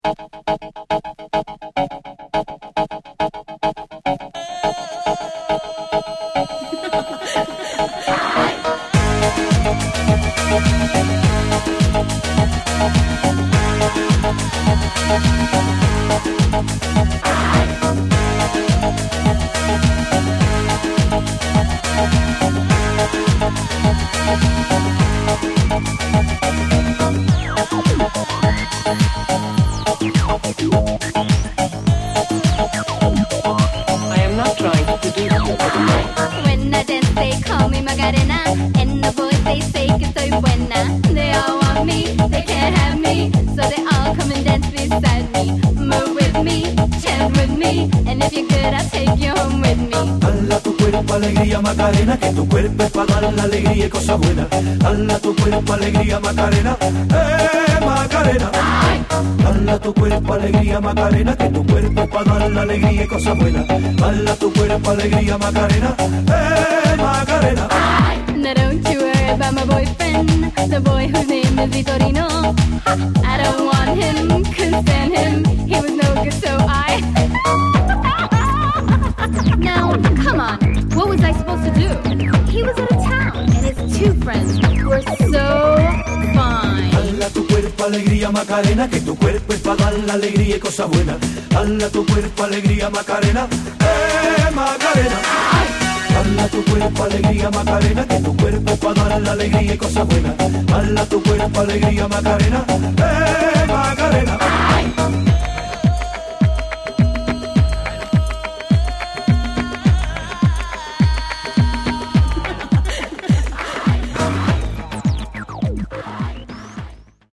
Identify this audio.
Genre: Latin